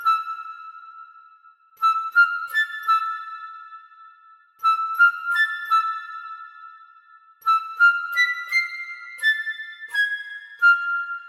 嘻哈长笛
标签： 85 bpm Hip Hop Loops Flute Loops 1.90 MB wav Key : Unknown
声道立体声